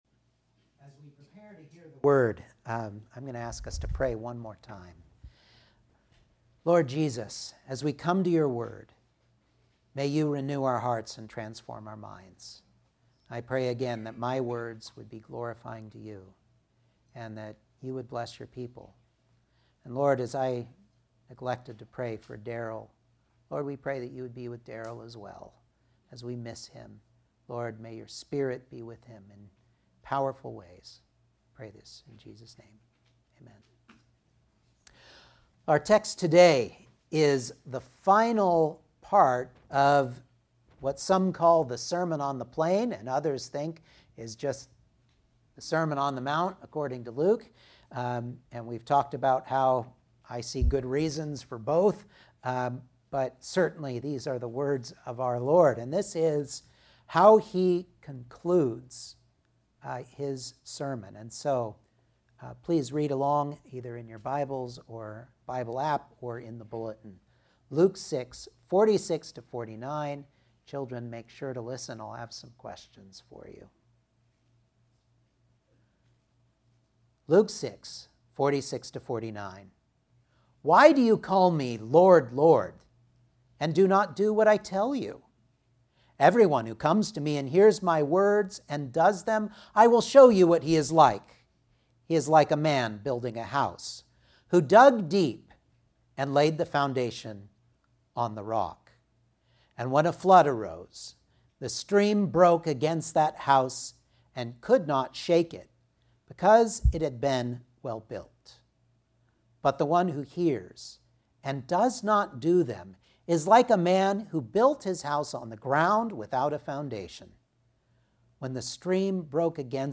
Luke 6:46-49 Service Type: Sunday Morning Outline